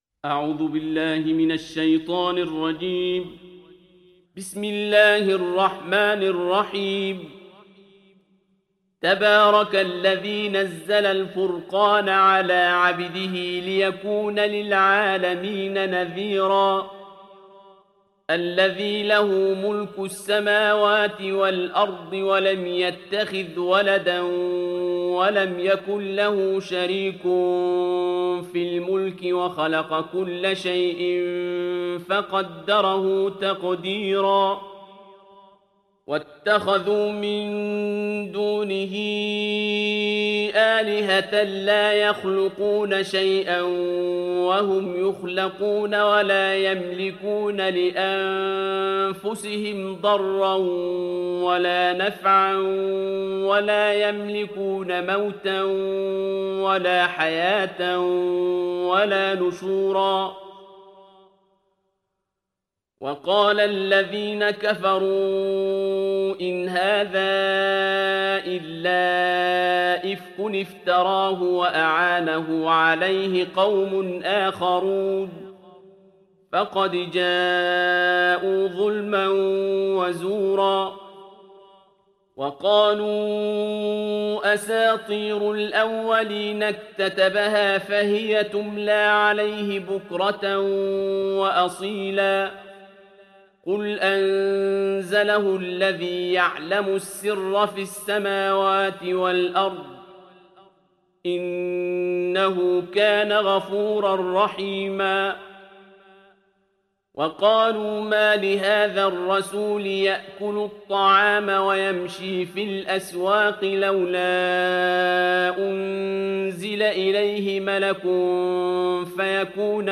Sourate Al Furqan Télécharger mp3 Abdul Basit Abd Alsamad Riwayat Hafs an Assim, Téléchargez le Coran et écoutez les liens directs complets mp3